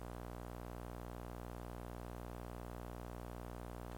Buzz on Recording, Not Sure What to Do
My rig usually runs condenser mic → Preamp → PC.
The buzz is a classic example of “mains hum”.
As you can see, those spikes in the spectrum (the buzz) go up from 60 Hz to over 3000 Hz.
One thing that I notice is that the buzz is exactly the same in both left and right channels, so that rules out a lot of possibilities.
It is “typical” of really severe mains hum.